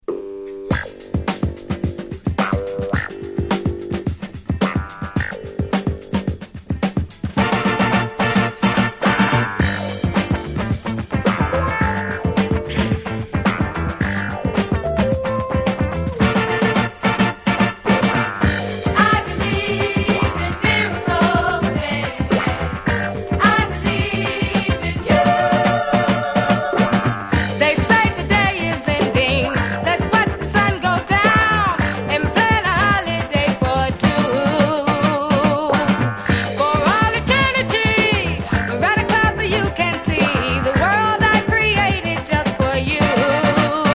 鉄板Dance Classic !!
音質もバッチリなので、Play用にはコチラで十分かと。